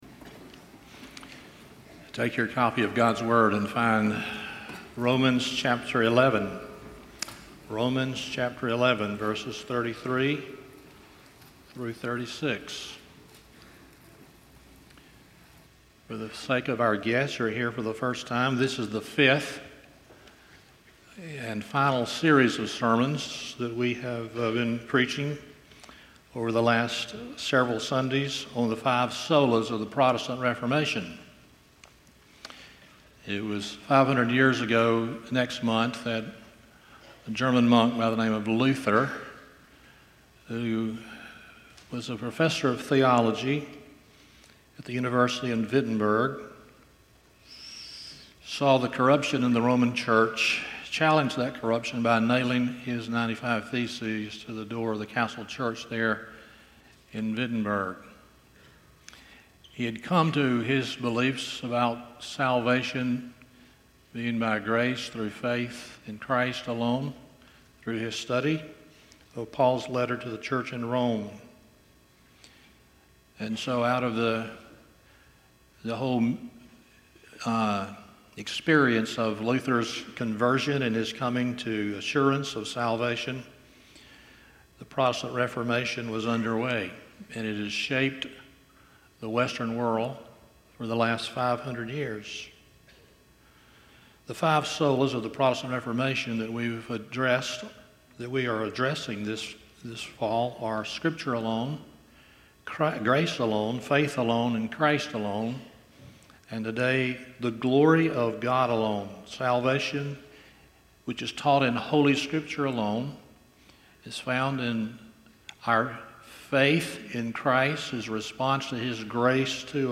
Romans 11:33-36 Service Type: Sunday Morning Our theology will dictate our doxology.